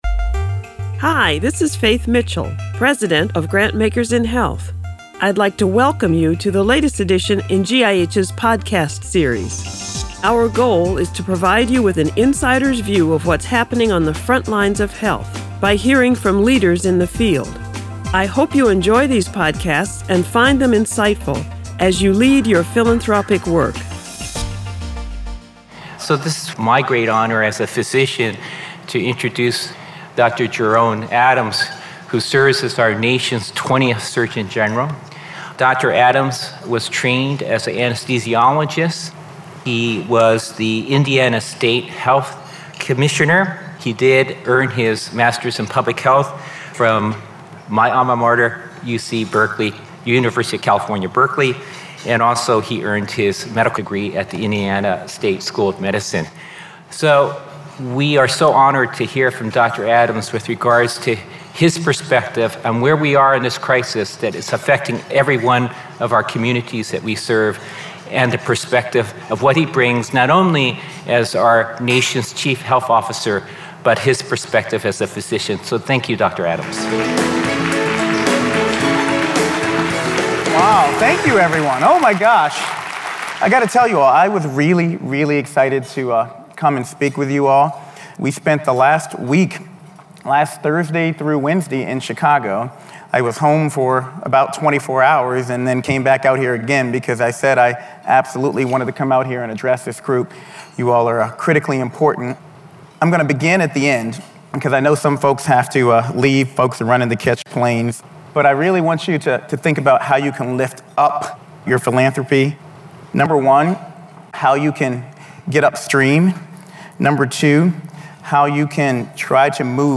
2019 Annual Conference Plenary Remarks: Jerome Adams - Grantmakers In Health
2019-Annual-Conference-Plenary-Remarks-Jerome-Adams.mp3